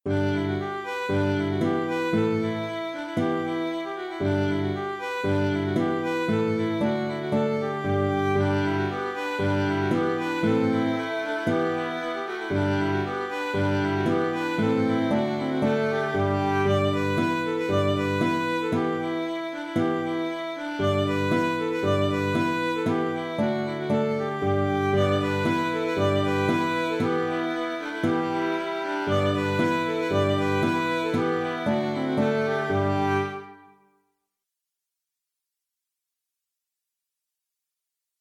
Polka du Kerry (Polka) - Musique irlandaise et écossaise